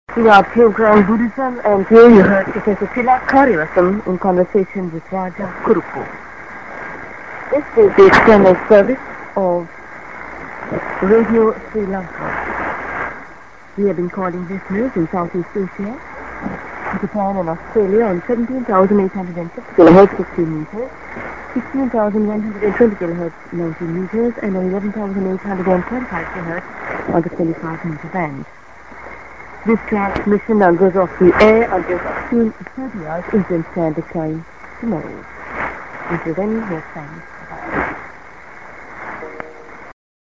a　End ID+SKJ(women)